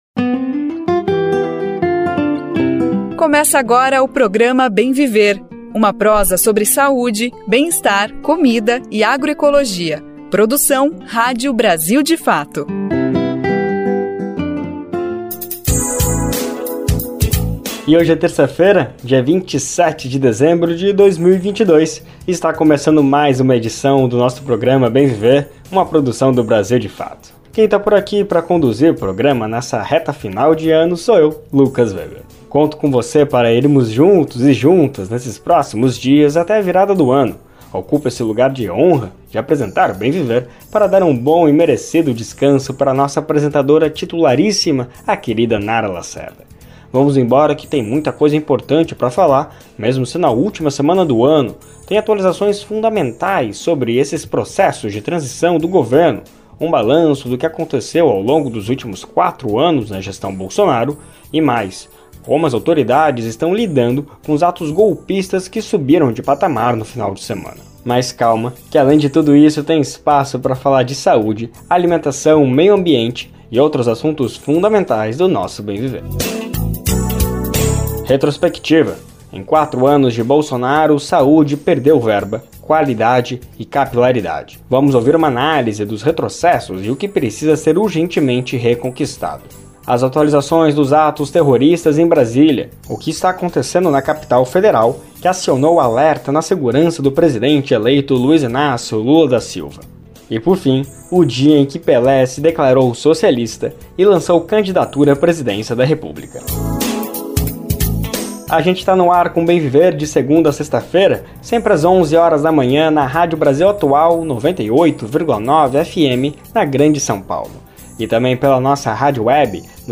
Programa desta terça-feira traz as atualizações dos atos terroristas em Brasília e o Pelé se declarando socialista